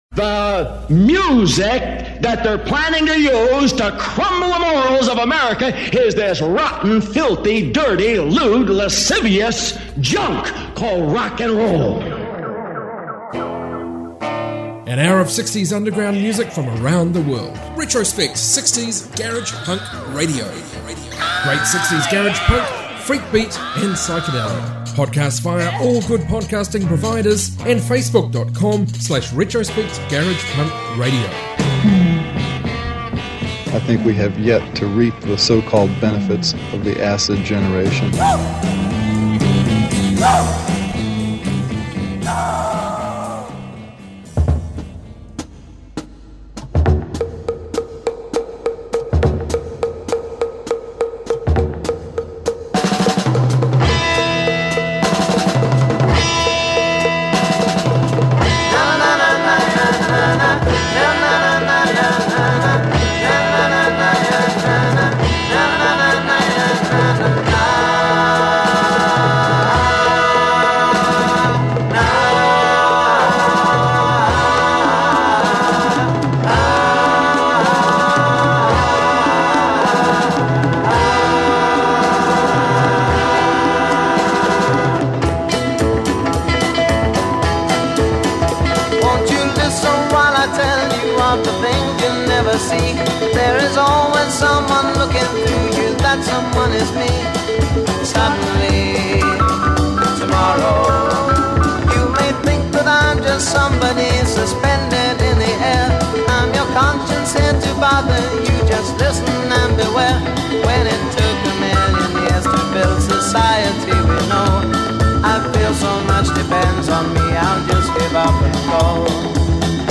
60s garage rock